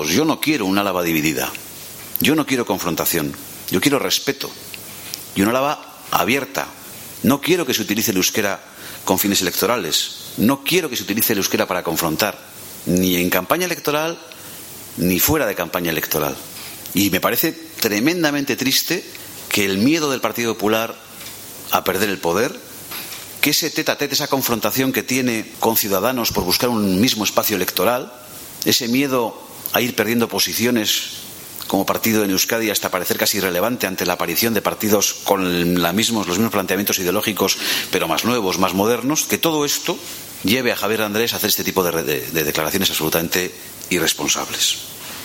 Ramiro González en rueda de prensa actualidad política - euskara (3)